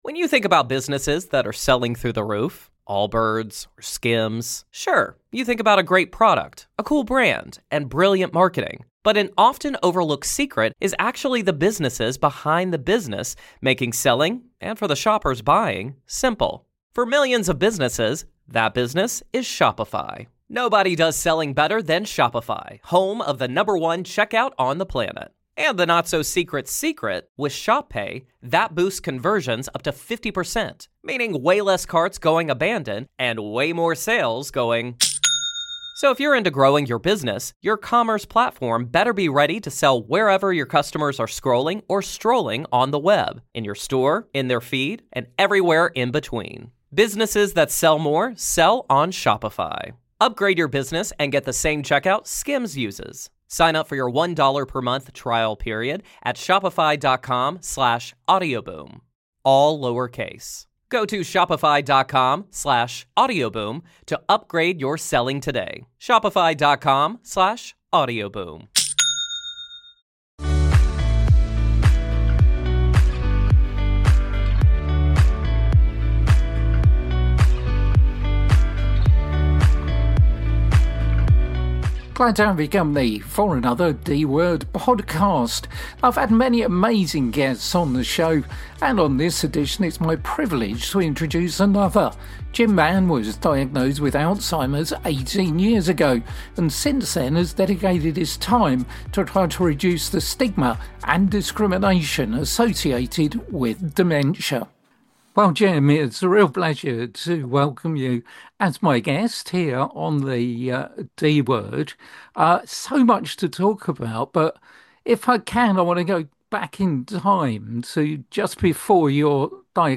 The ‘D’ Word is the UK’s only dementia-focused radio show. Talking to experts and people living with dementia